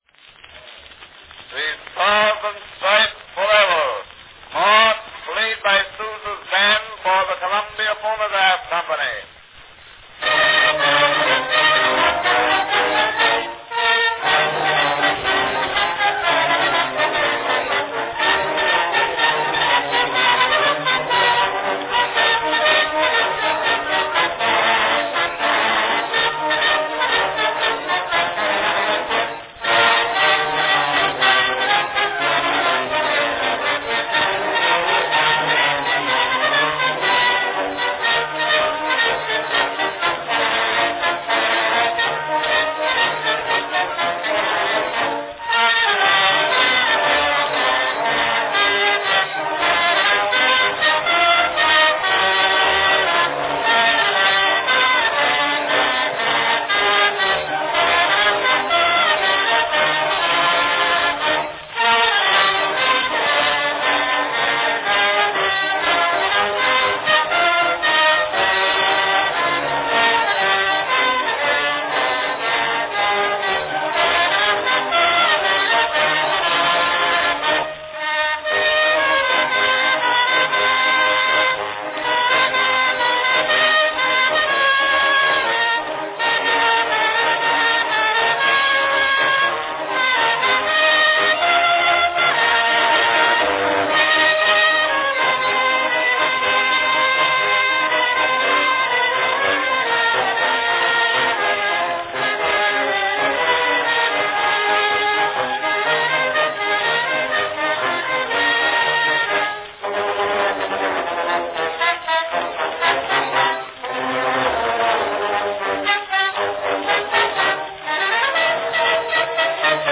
5-inch "grand" cylinder
Category Band